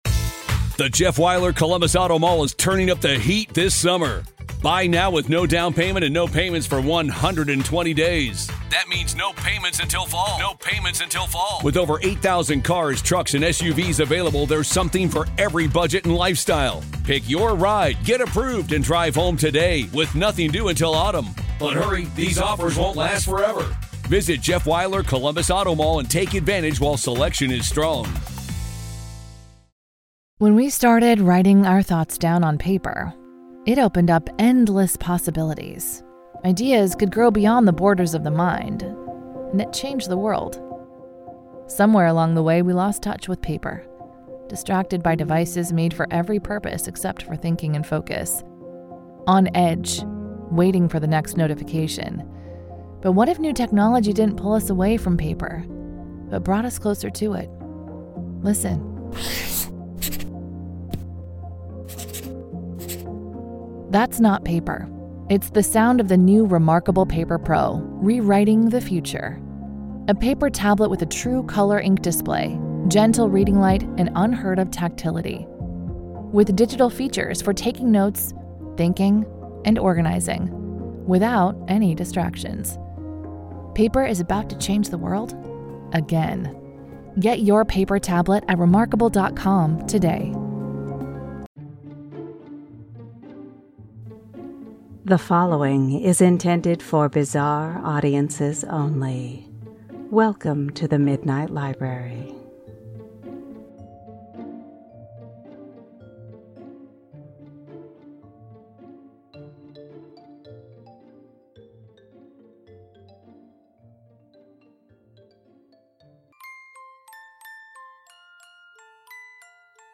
The accounts are true, and the reading carries a "heartbreak" warning due to its intense sadness and few graphic facts.